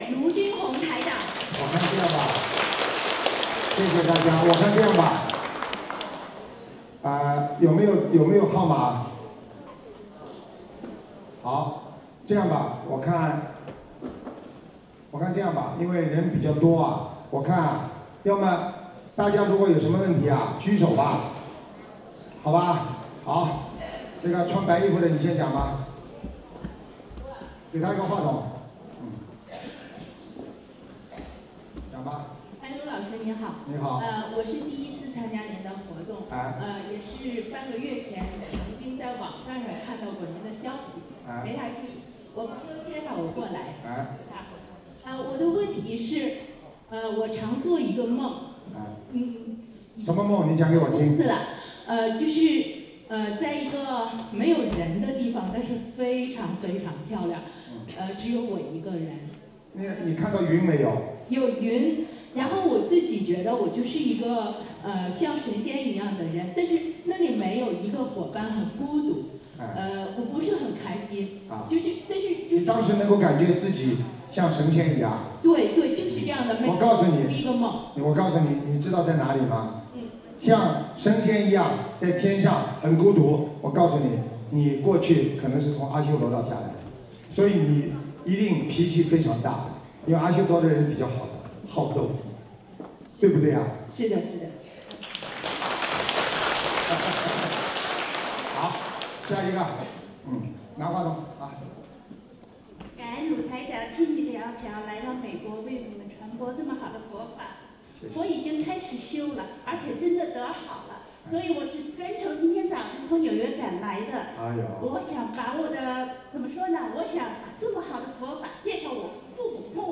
视频：89_2012年09月29日 美国 哈佛大学演讲会开示 - 2012法会视频 百花齐放
首页 >>弘法视频 >> 法会视频